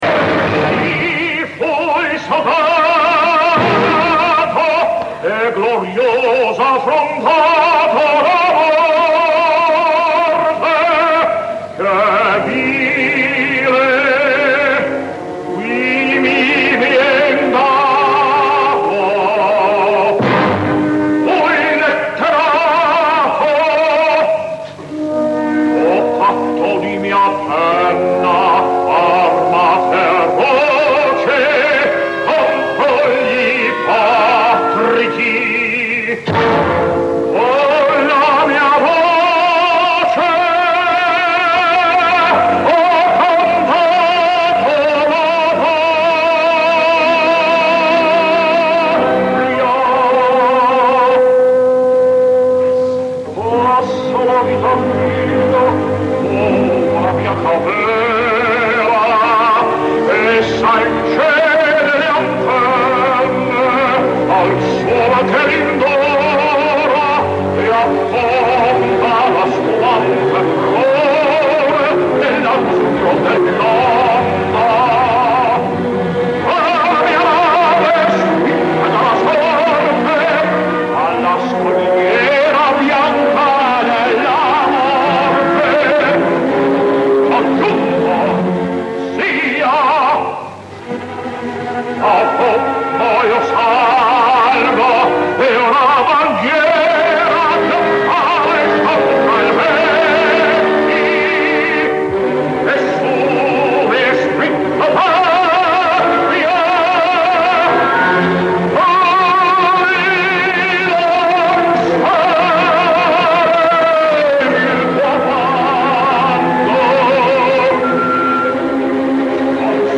El so no es precisament digital, tot i que digne, però el valor dels àudios que avui escoltarem ben bé valen una certa condescendència tècnica.
El so ja sé que no és molt bo, però el document val la pena. Escolteu ara l’ària del tercer acte “Si fui soldato”, exagerada fins al límit. Ni la claca del Liceu, tan habitual per aquells anys, va saber iniciar els aplaudiments, me’ls imagino clavats a les butaques més extremes del quart i cinquè pis.